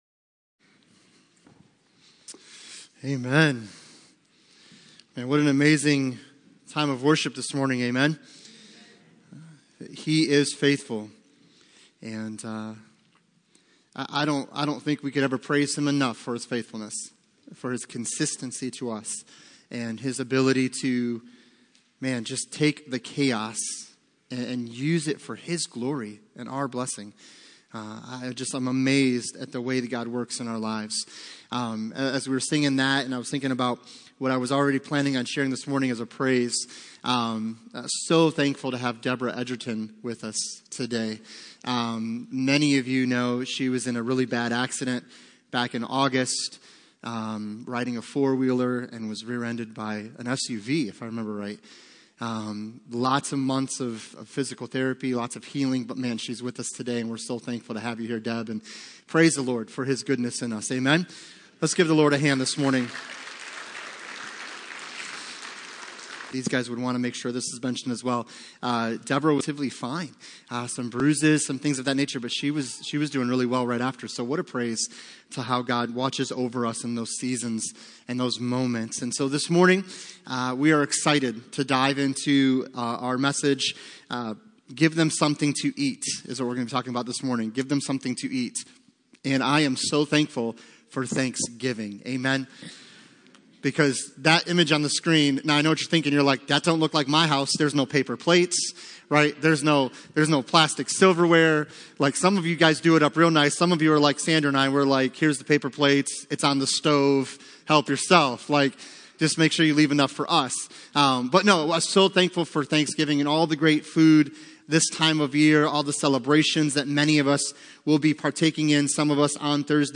Passage: Matthew 14:14-21 Service Type: Sunday Morning